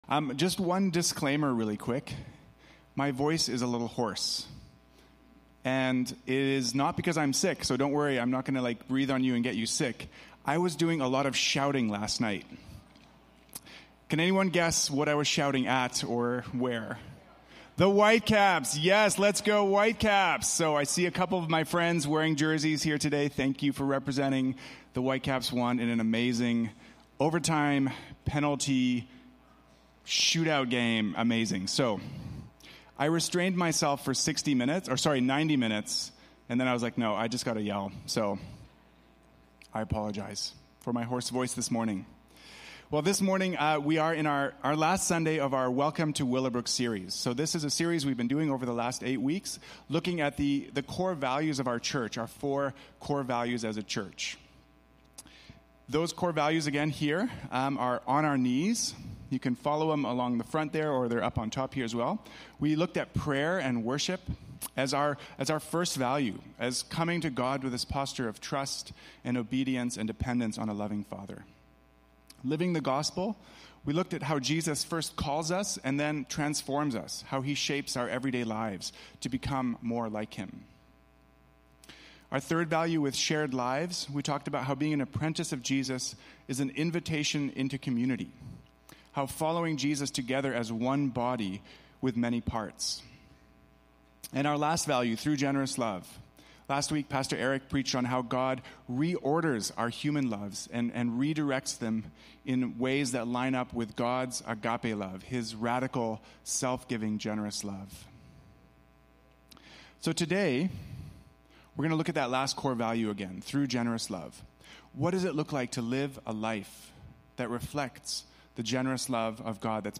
Willowbrook Sermons | North Langley Community Church